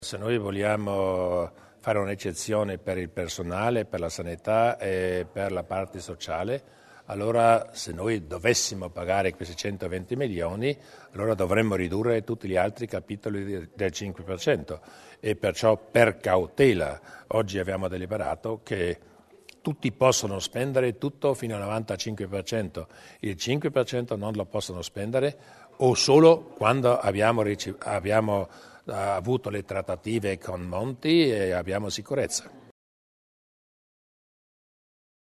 Il Presidente Durnwalder sugli efetti della manovra del Governo Monti